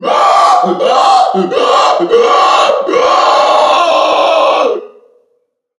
NPC_Creatures_Vocalisations_Puppet#1 (hunt_01).wav